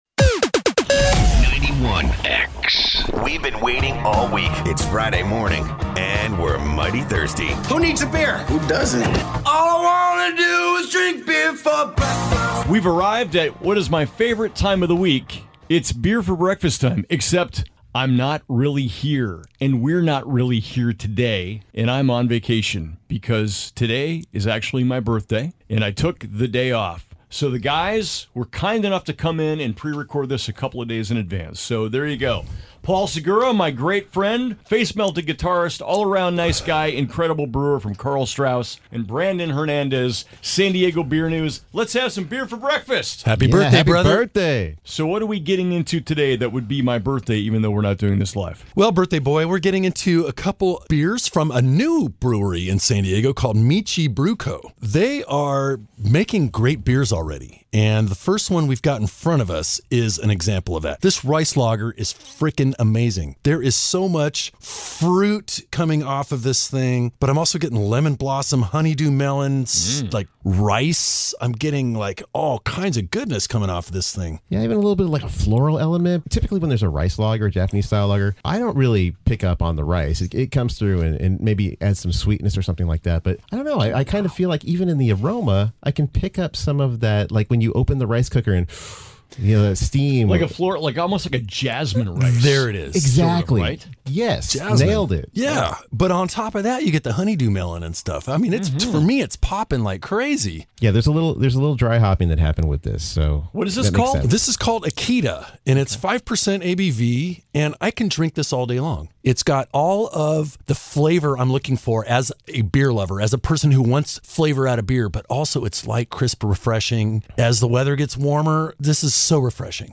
This week, we’re pulling back the curtain to share that our latest segment is [gasp] pre-recorded.